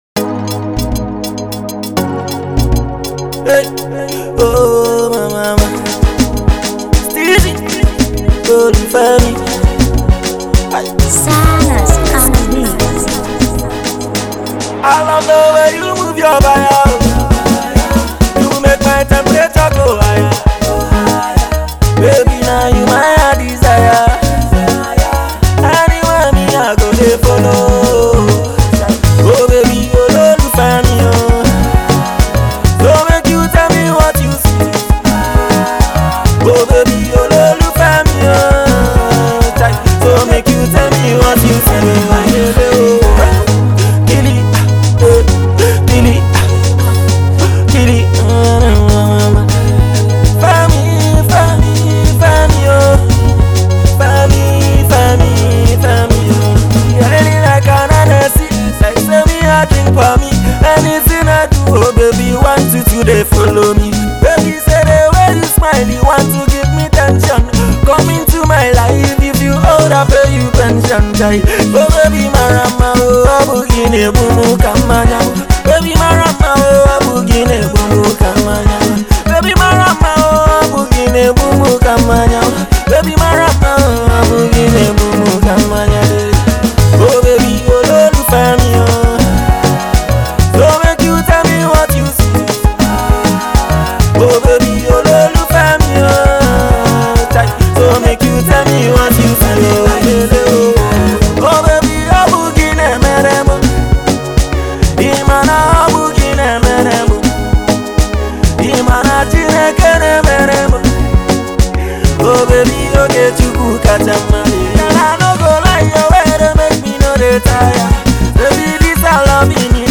Delightful Pop Track
Its kind of Catchy